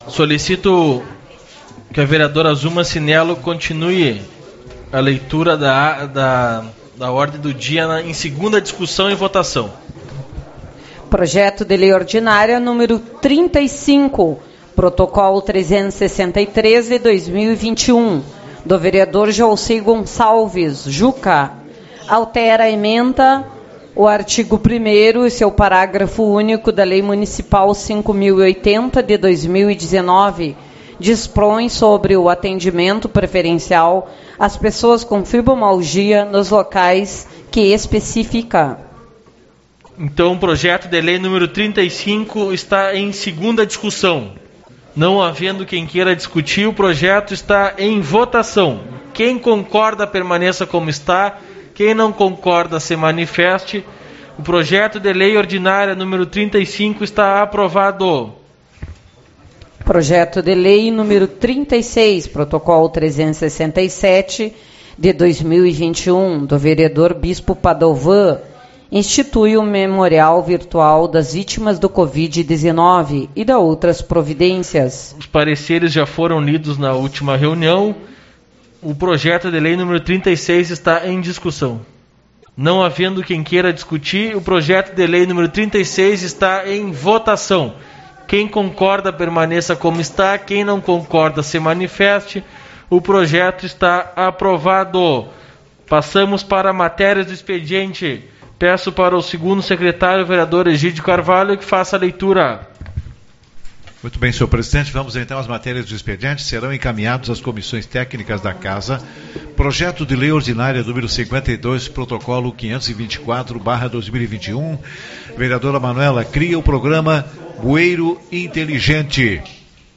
25/05 - Reunião Ordinária